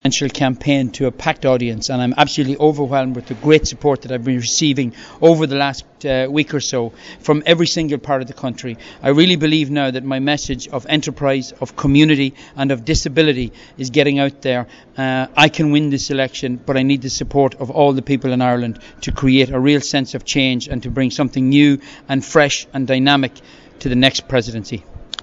Seán Gallagher at his campaign launch